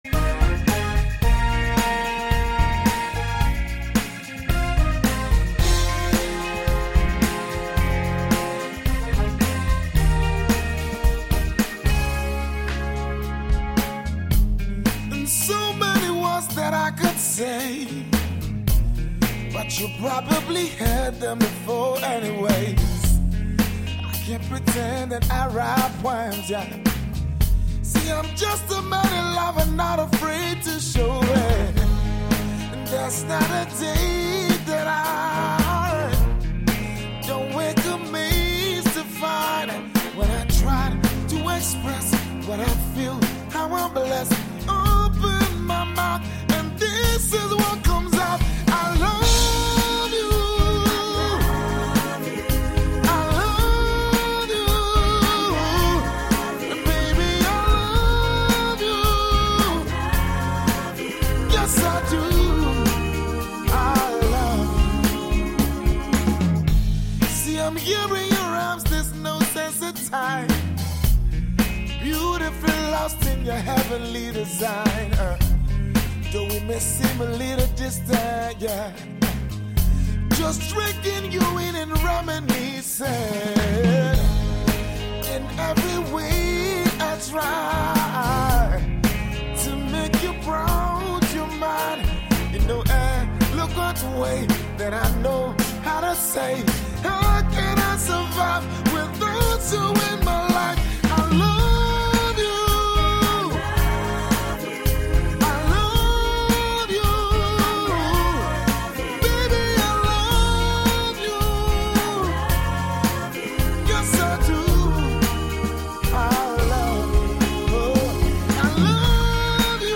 Nigeria’s top soul singer
energetic and passionate song